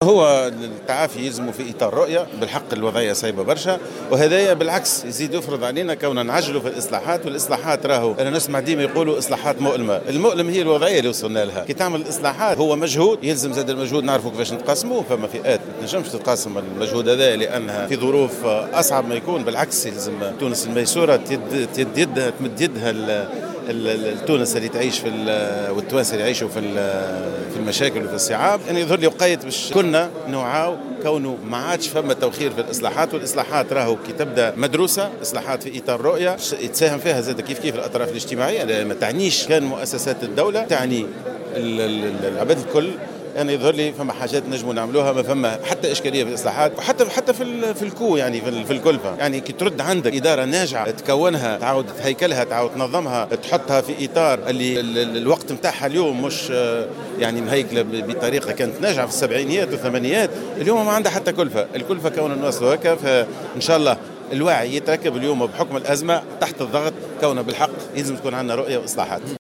وأضاف جمعة في تصريح لمراسلة الجوهرة اف ام على هامش حضوره افتتاح الدورة 33 لأيام المؤسسة بسوسة، إن الإصلاحات المدروسة، كفيلة بإعادة هيكلة المؤسسات وإنقاذها، دون تكلفة مالية.